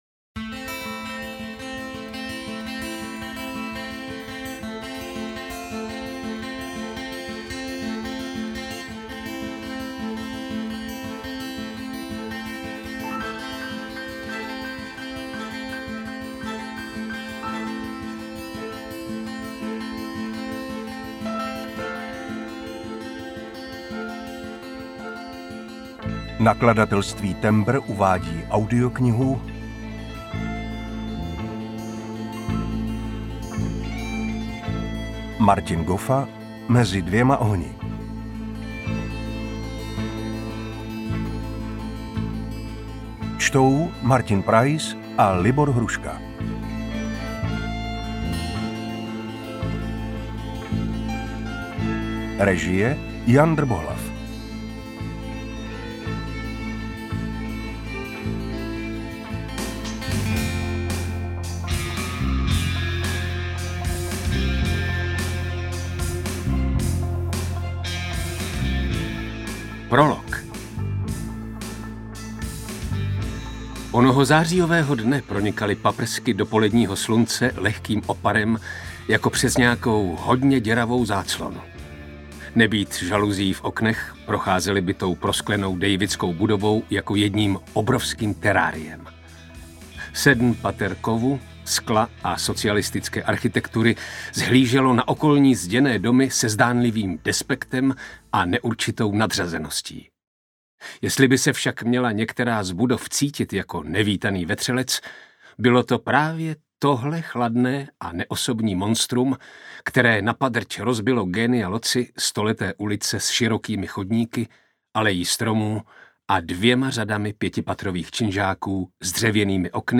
UKÁZKA Z KNIHY
audiokniha_mezi_dvema_ohni_ukazka.mp3